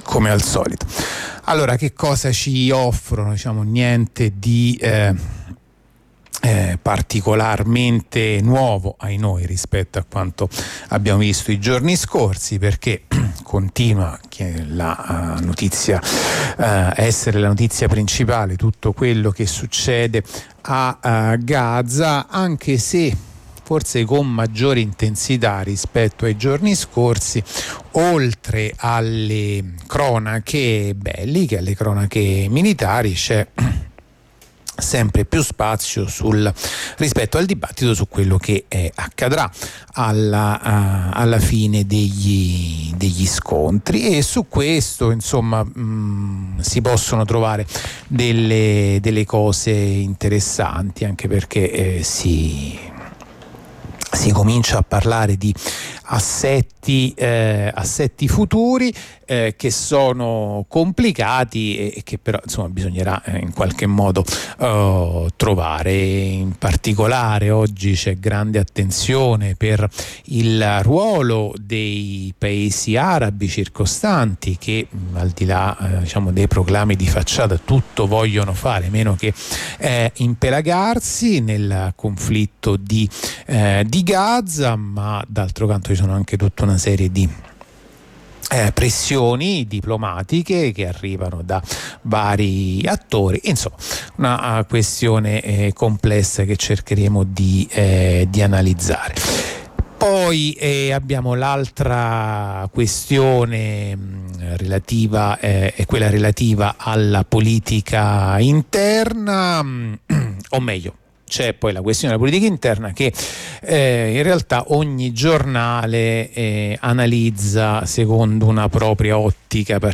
La rassegna stampa di radio onda rossa andata in onda martedì 7 novembre 2023